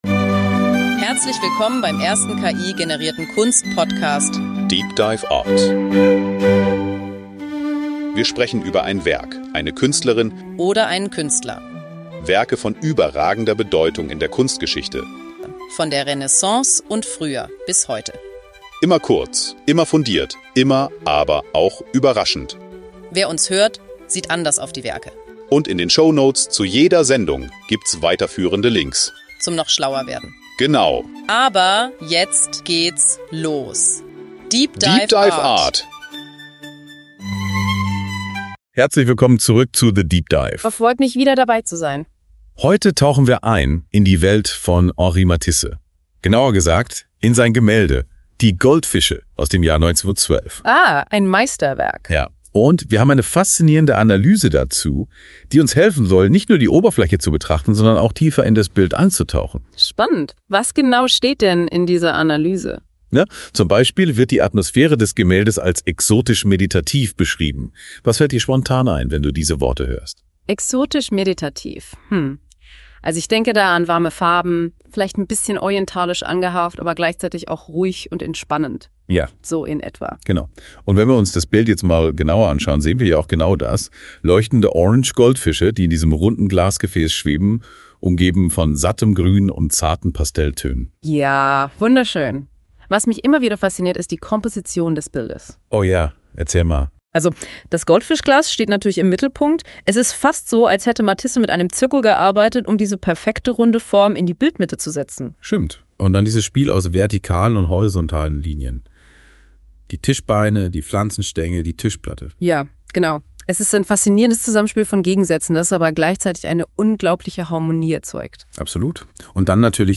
Die Episode schließt mit der Erkenntnis, dass Kunst eine wichtige Rolle in unserem Leben spielt und uns helfen kann, die Welt mit anderen Augen zu sehen. deep dive art ist der erste voll-ki-generierte Kunst-Podcast.
Die beiden Hosts, die Musik, das Episodenfoto, alles. dda ist für alle, die wenig Zeit haben, aber viel Wissen wollen.